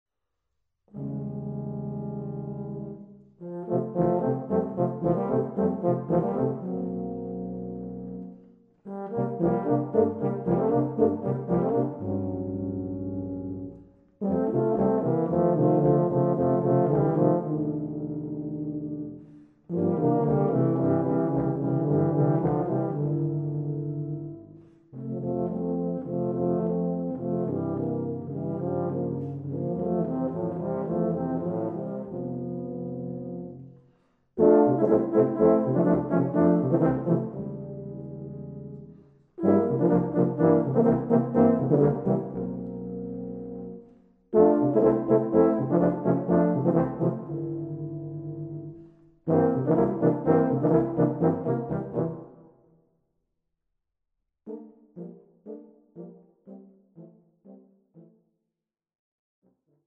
Gattung: für Tubaquartett
Besetzung: Instrumentalnoten für Tuba